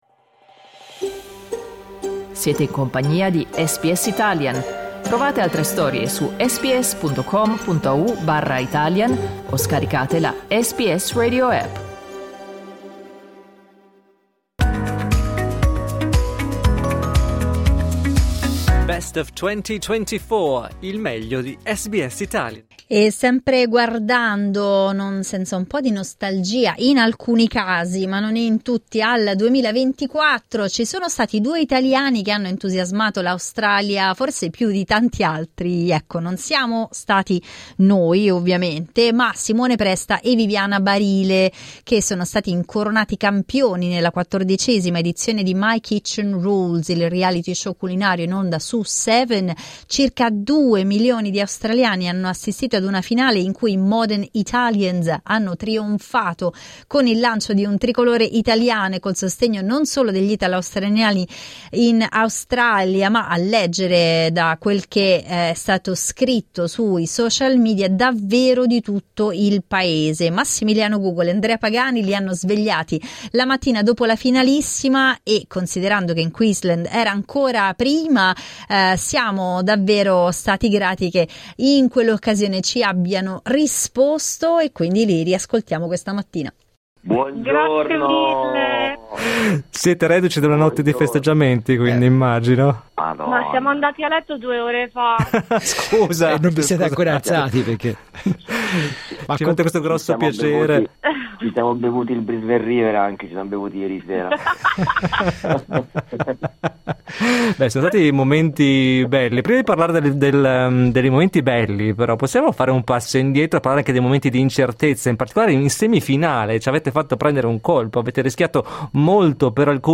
Ascolta l'intervista La creatività italiana vince a "My Kitchen Rules 2024" SBS Italian 12:15 Italian Ascolta SBS Italian tutti i giorni, dalle 8am alle 10am.